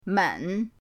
men3.mp3